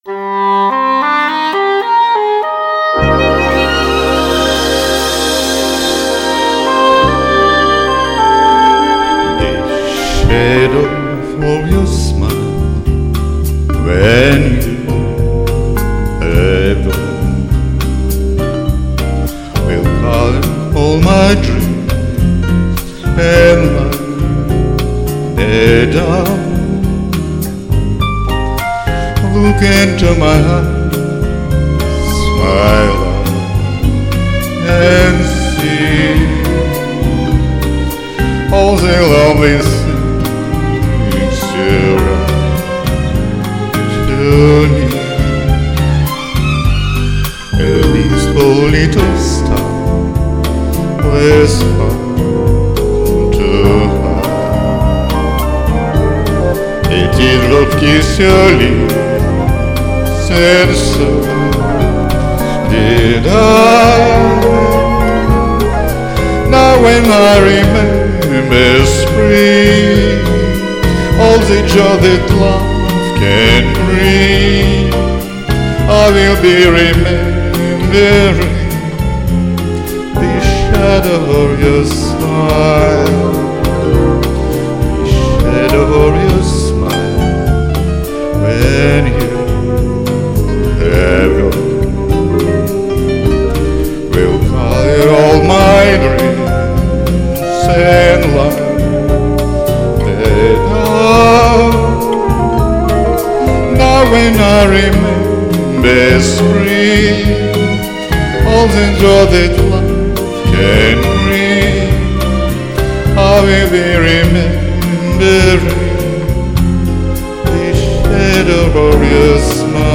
Правда тембрально оба хороши!